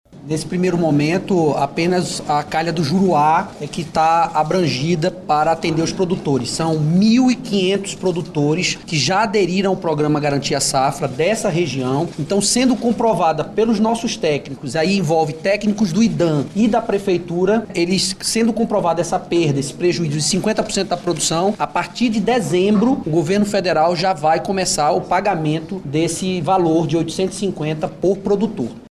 Entre as ações de planejamento, a Secretaria de Estado e Produção Rural traz o programa garantia-safra, que segundo o Secretário da Sepror, Petrúcio Magalhães, oferece condições para os agricultores prejudicados por causa da estiagem.
SONORA02_PETRUCIO.mp3